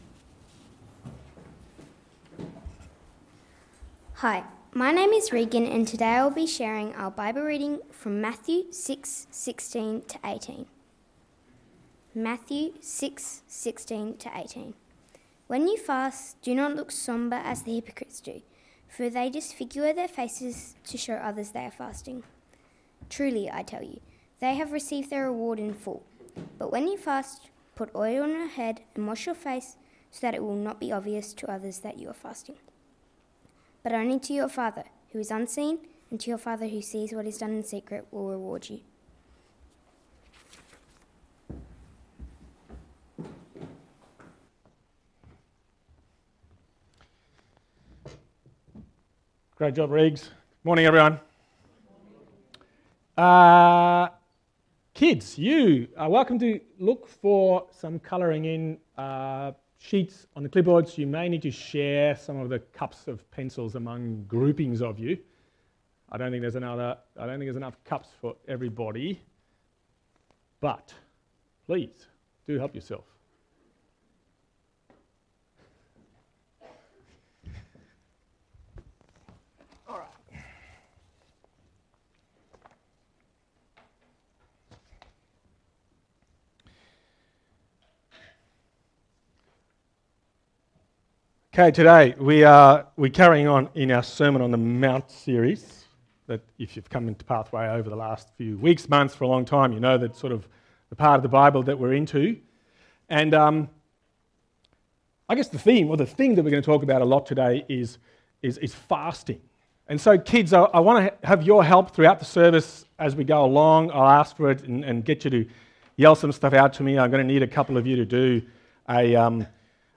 Sermon on the Mount Part 13 - Fasting | Pathway to Life API